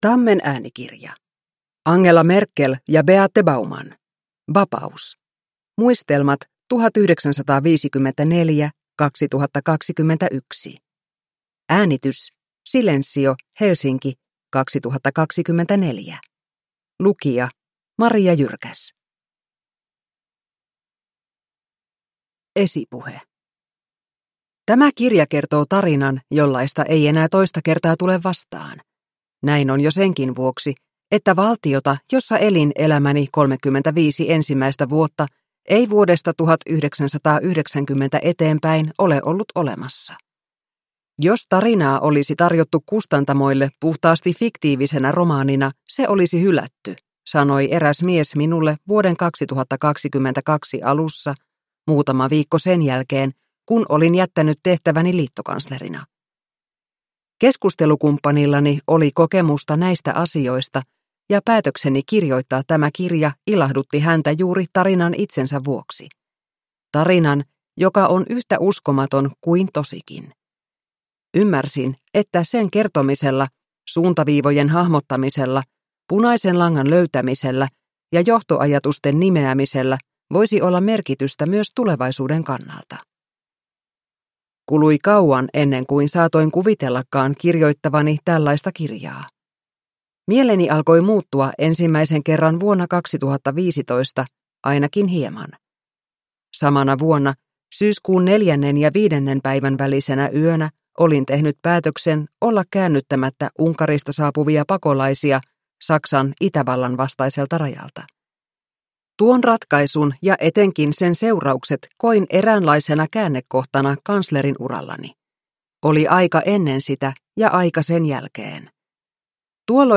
Vapaus (ljudbok) av Angela Merkel